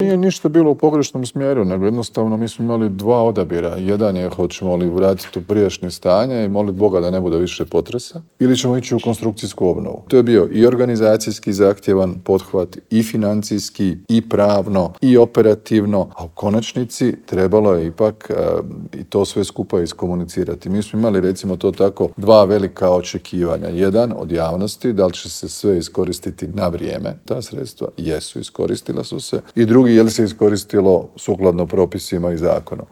ZAGREB - Na petu godišnjicu potresa na Banovini premijer Andrej Plenković u Intervjuu tjedna Media servisa istaknuo je da je ukupno za obnovu svih područja koja su stradala u ovom, ali i zagrebačkom potresu, utrošeno 4,3 milijarde eura, od čega je 1,7 milijardi izdvojeno samo za Banovinu.